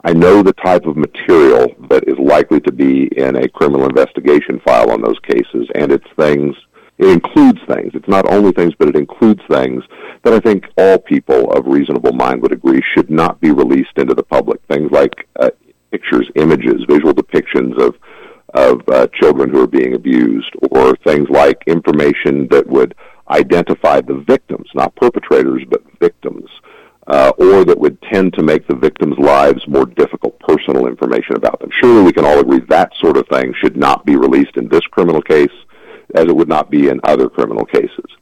That’s the take from Kansas Second District Congressman Derek Schmidt on KVOE’s airwaves late last week as he commented on the ongoing debate on how the trump administration should handle the Jeffrey Epstein files.